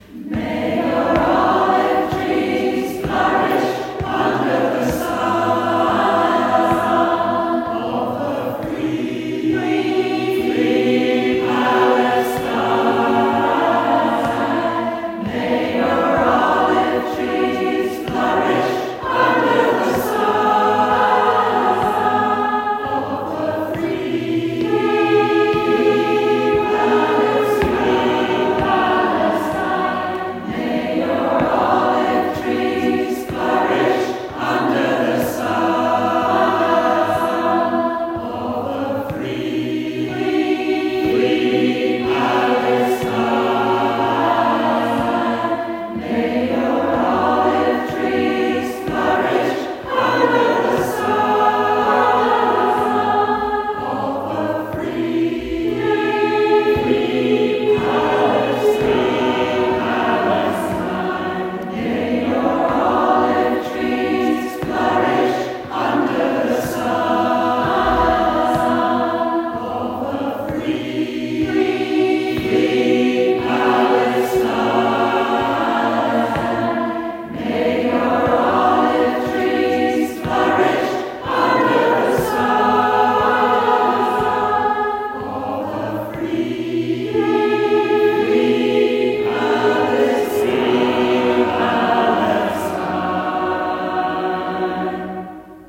PiH singing at our rehearsal on 13th Dec ’25: